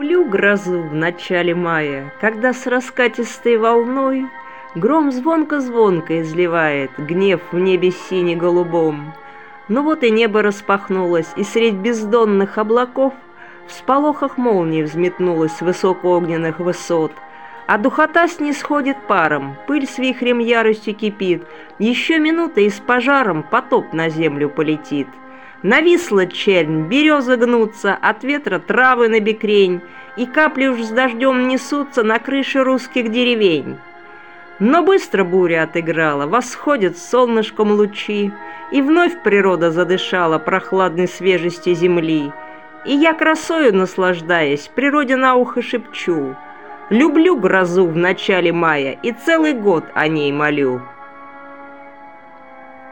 Музыка классики Озвучка автора